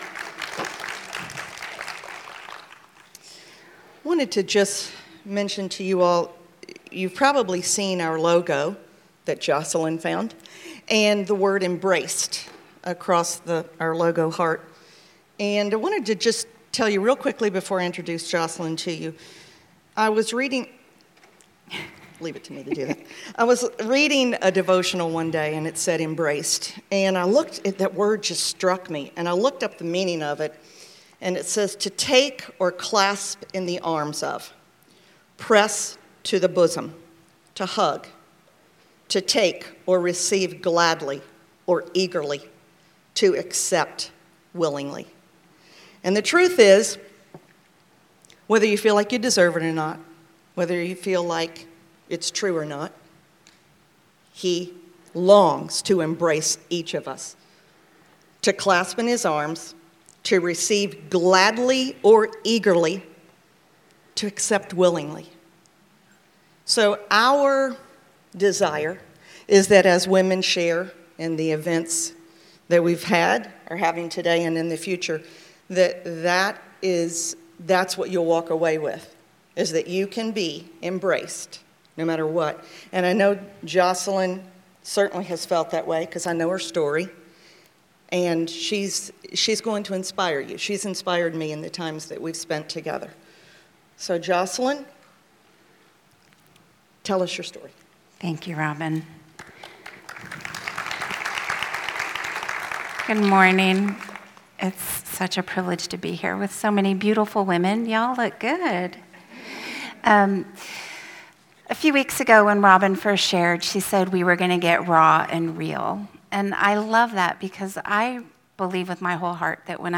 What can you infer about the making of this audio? I recently had the honor of sharing my testimony with the Ministry for Women at my church.